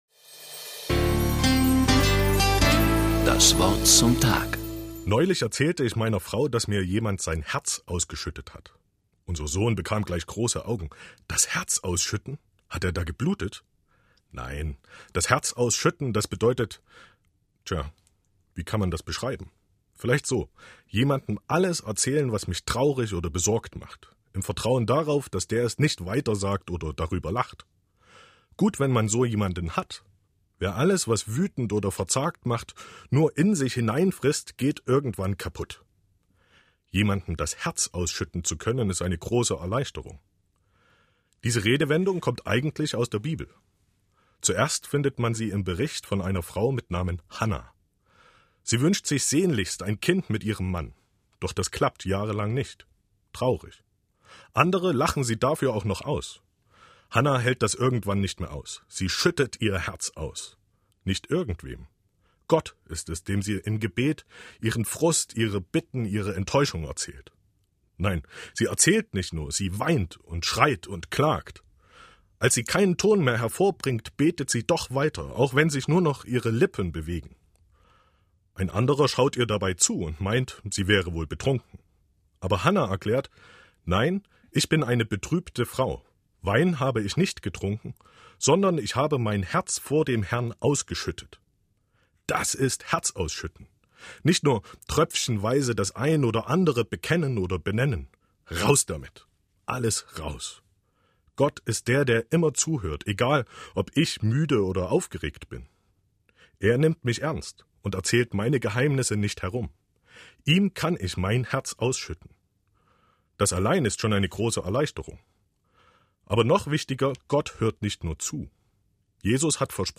In der Woche vor dem Ewigkeitssonntag hatte unsere Kirche in Sachsen die Möglichkeit, das „Wort zum Tag“ im MDR zu gestalten.